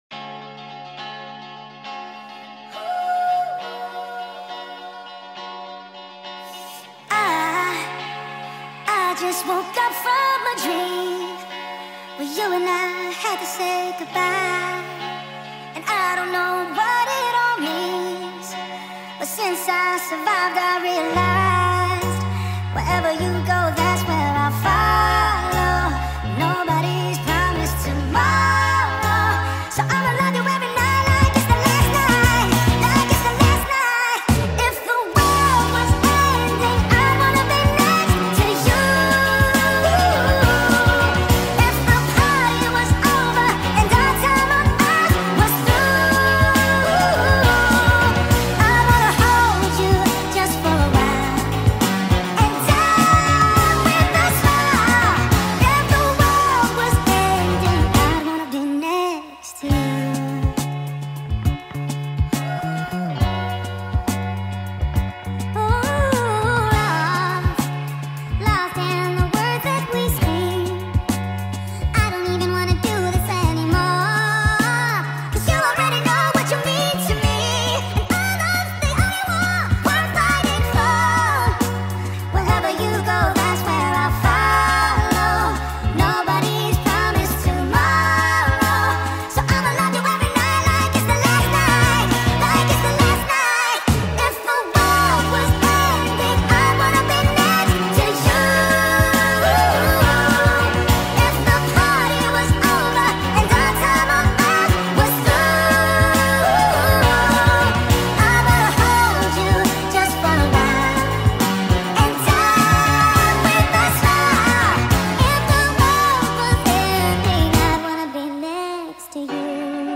با ریتمی تند
فضایی رمانتیک و درام